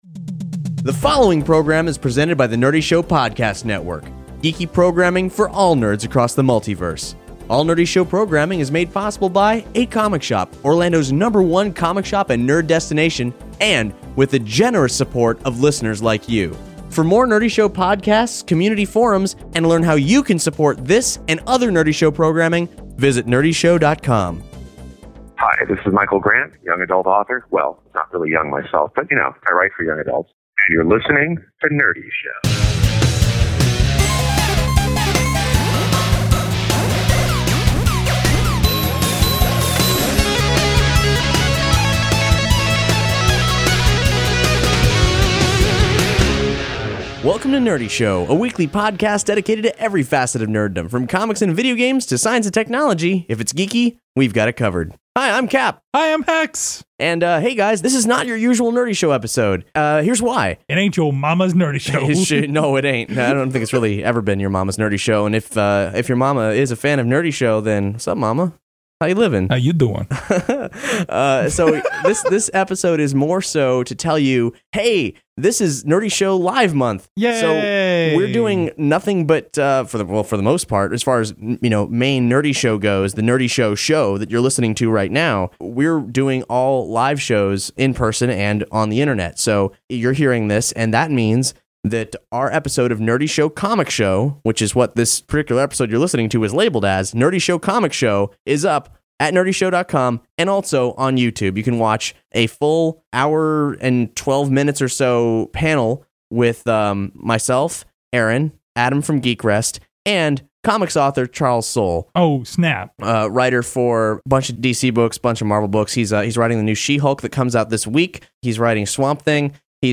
Introducing Nerdy Show Comic Show LIVE – a mayhem-filled comics panel.
It’s a live interview with comics author Charles Soule (Red Lanterns, Superman/Wonder Woman, Swamp Thing, Thunderbolts, She-Hulk, Inhuman, and Letter 44) but since it’s Nerdy Show, it’s anything but normal.
There’s audience participation, a Q&A, and plenty of surprises.